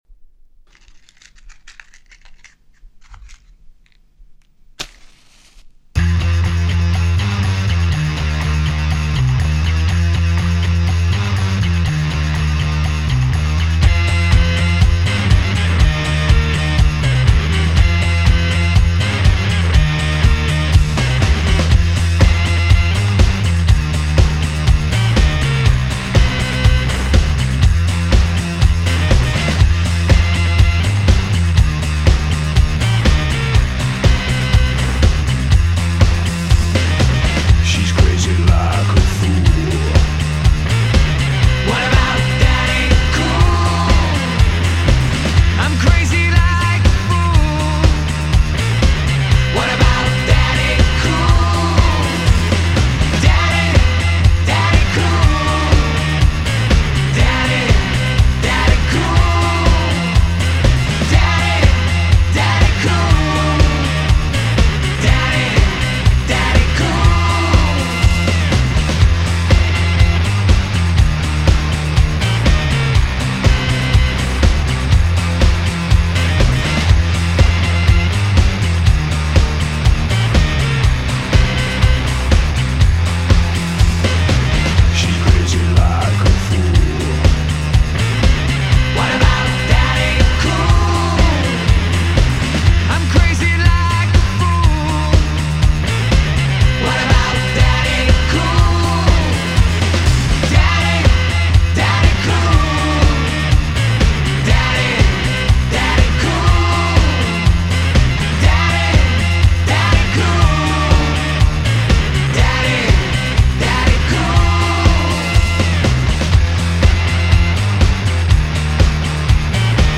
Genre: Alternative Rock